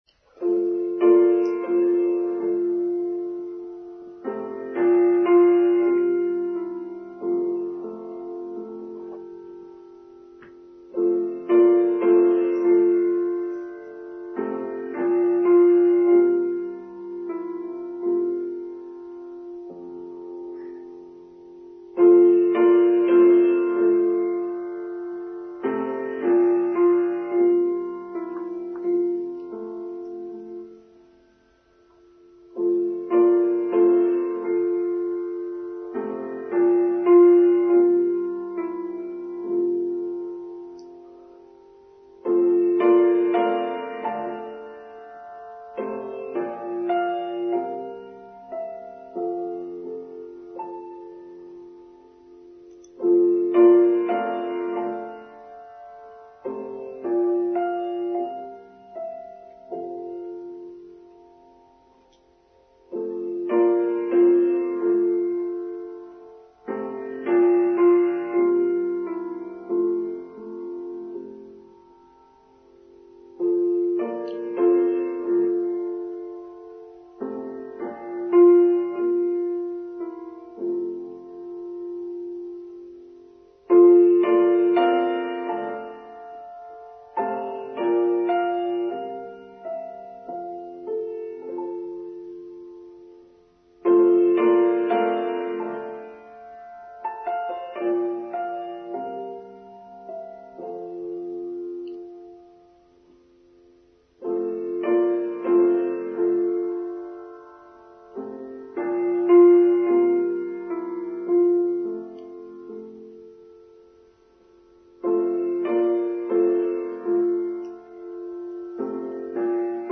Cleanliness: Online Service for Sunday 19th February 2023